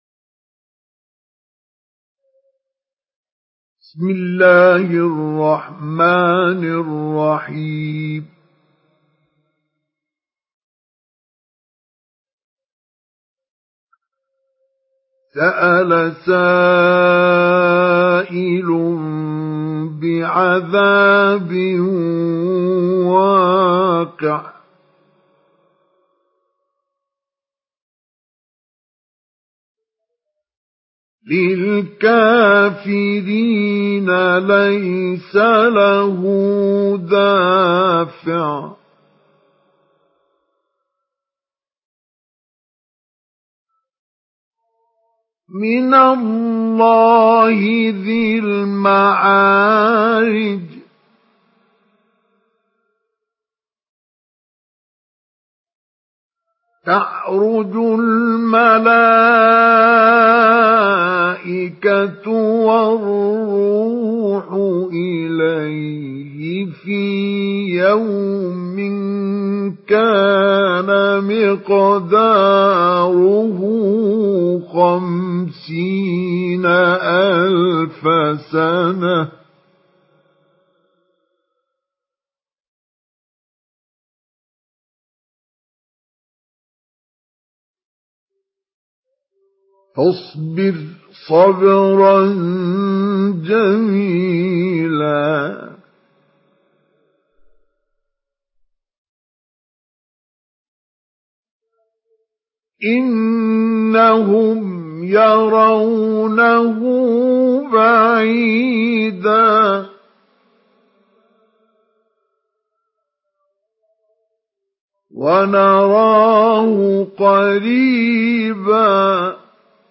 Surah Al-Maarij MP3 in the Voice of Mustafa Ismail Mujawwad in Hafs Narration
Surah Al-Maarij MP3 by Mustafa Ismail Mujawwad in Hafs An Asim narration.